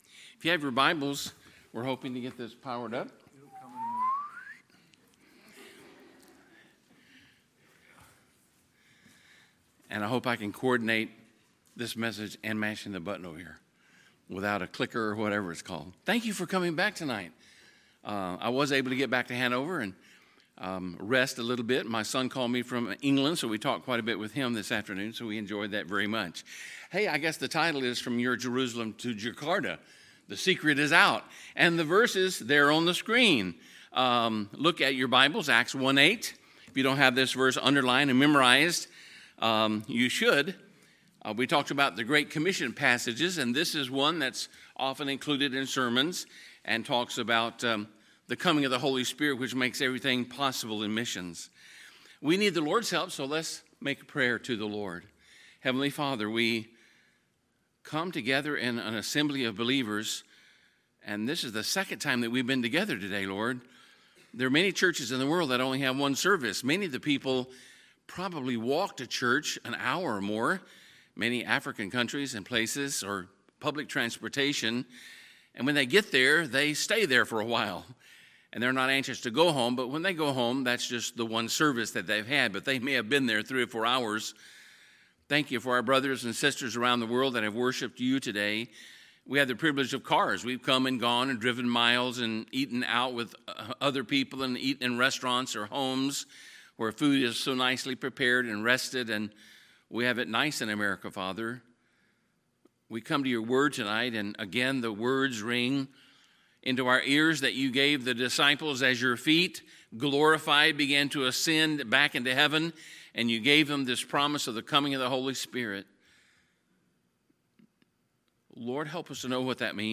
Sunday, September 23, 2018 – Sunday Evening Service